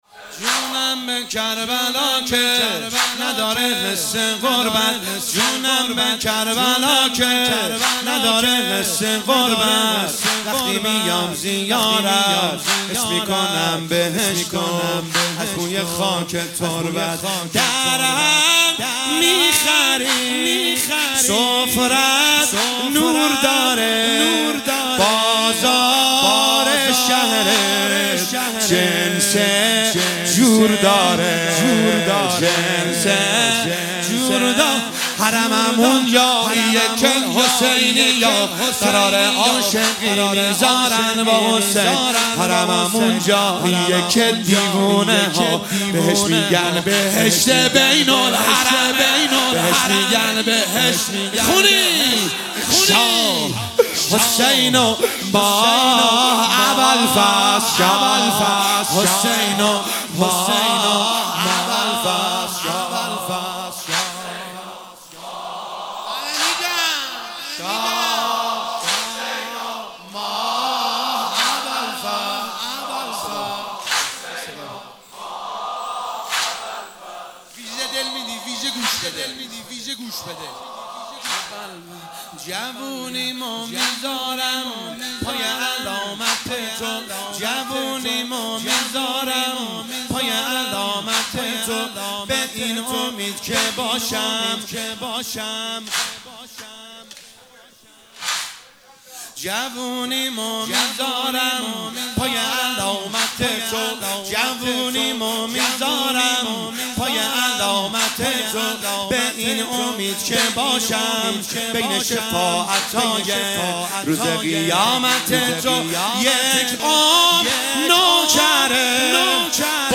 مداحی واحد شب هشتم محرم 1445
هیئت خادم الرضا قم